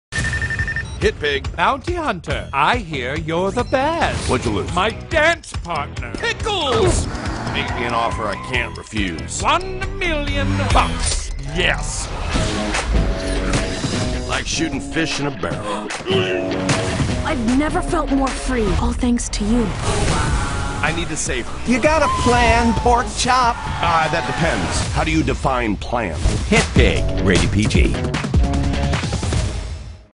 Spot 1 Spot 2 Joe CiprianoHitpig!Trailers Download This Spot